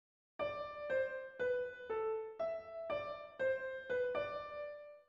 Counter subject
countersubject.mp3